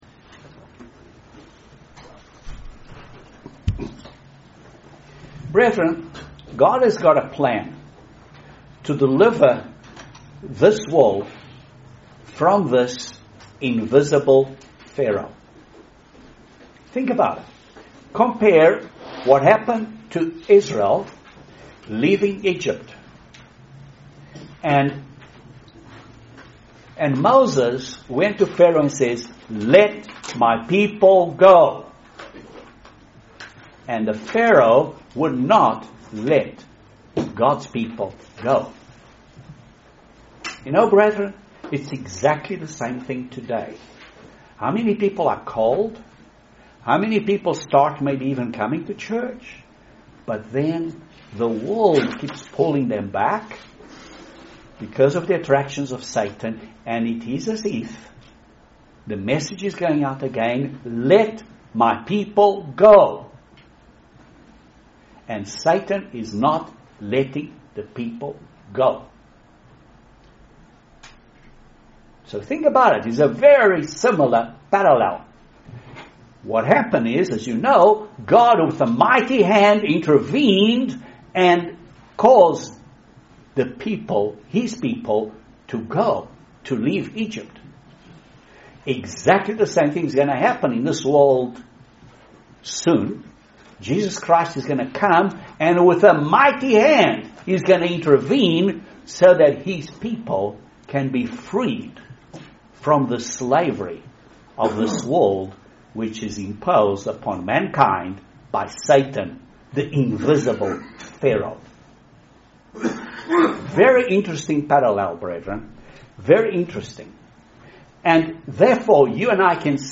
Eye opening study of the Exodus of the Israelites out of Egypt, and what God's Holy Scriptures say about keeping the Passover. We have to prepare Physically and Spiritually and this Sermon talks about doing those things also.